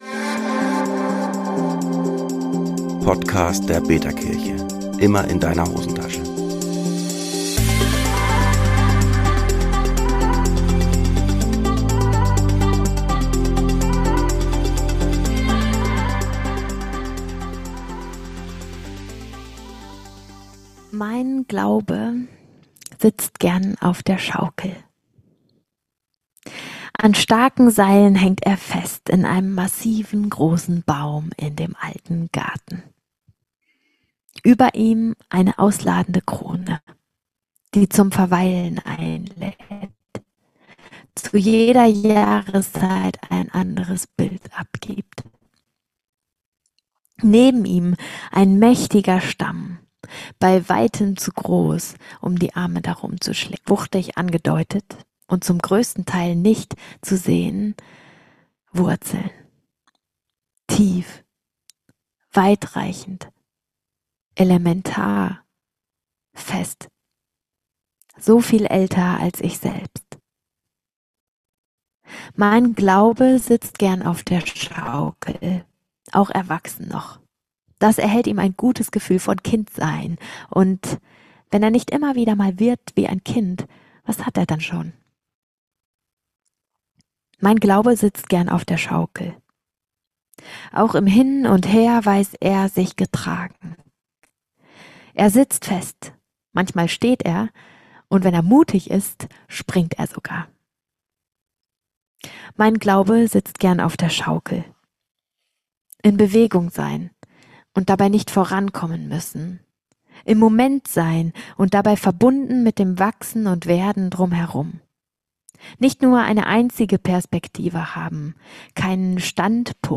Konzert
In dieser poetischen Momentaufnahme aus dem Konzertabend nimmt uns